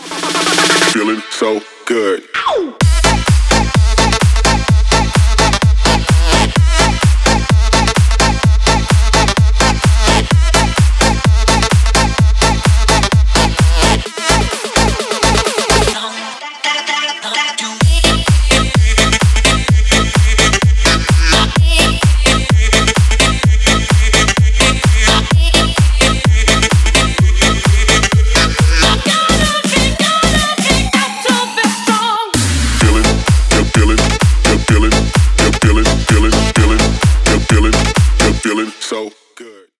Танцевальные # без слов # весёлые